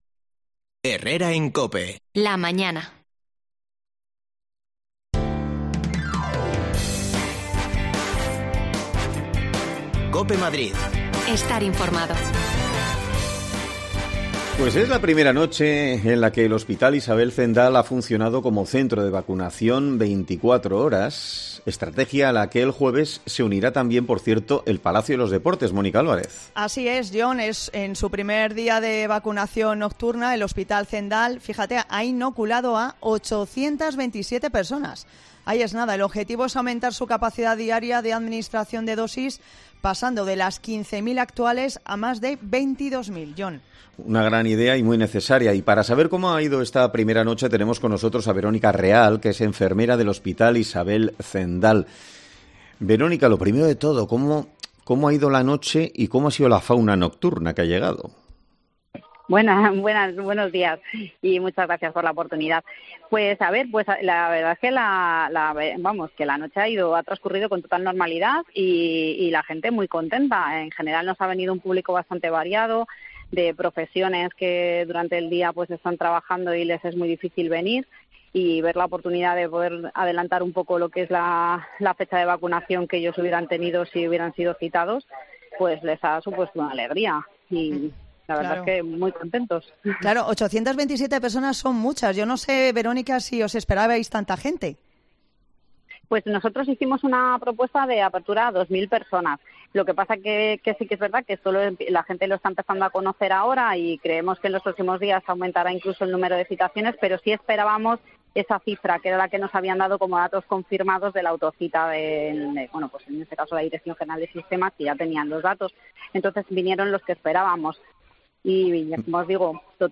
AUDIO: Más de 800 dosis de vacuna se ha puesto esta primera noche en el Hospital Zendal de Madrid. Hablamos con una de las enfermeras de este centro...